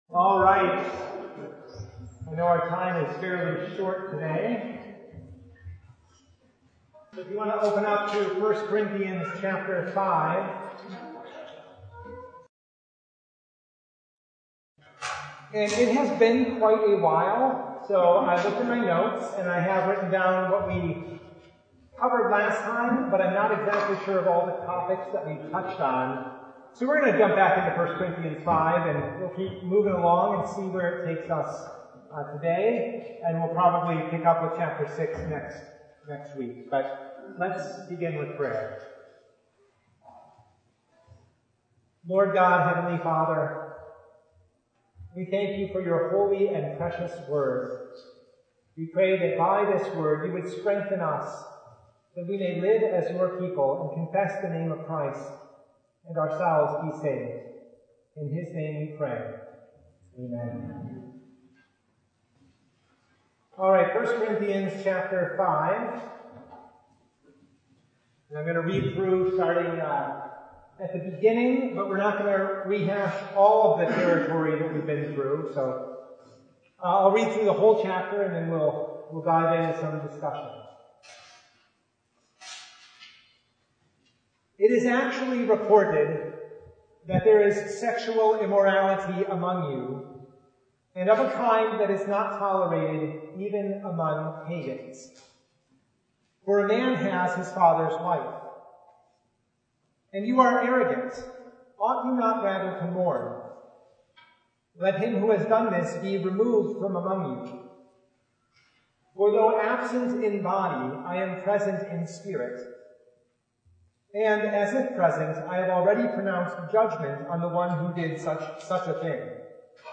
Service Type: Bible Hour
Bible Study